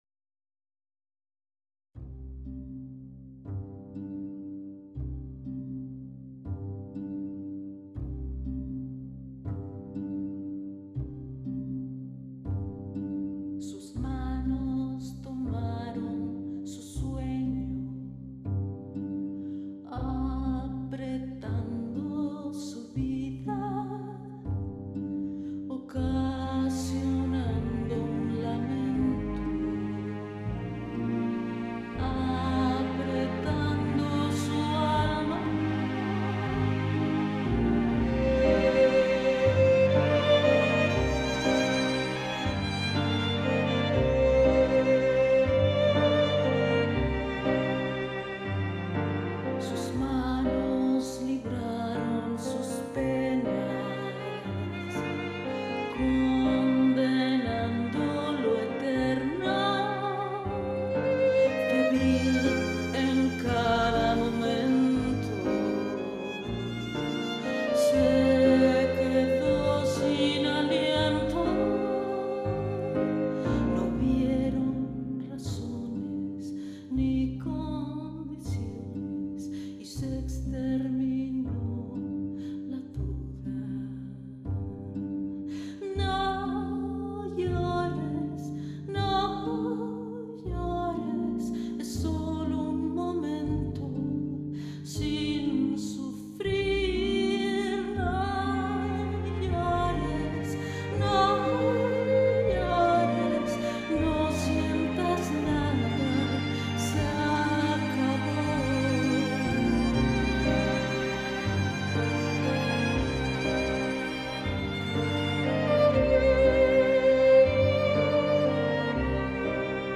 A very sensible and emotional score and a name to remember